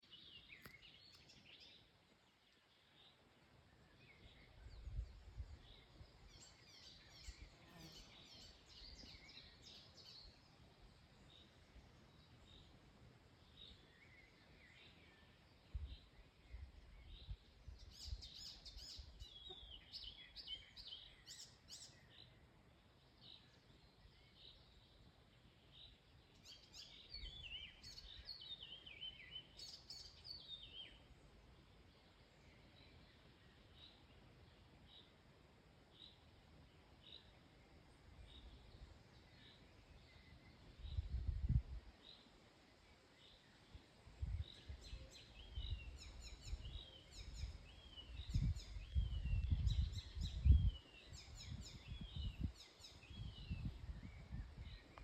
Putni -> Ķauķi ->
Iedzeltenais ķauķis, Hippolais icterina
StatussDzied ligzdošanai piemērotā biotopā (D)